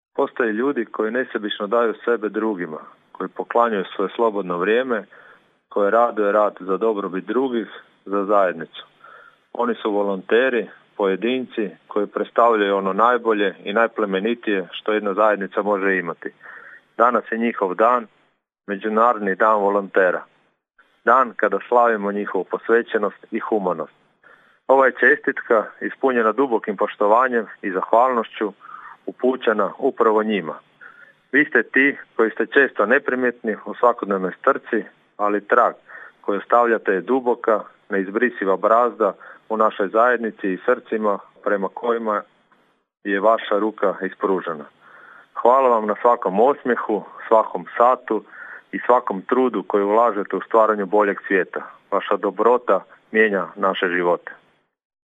Povodom Međunarodnog dana volontera, gradonačelnik Labina Donald Blašković uputio je čestitku svim volonterima koji nesebično daruju svoje vrijeme, znanje i energiju za dobrobit zajednice.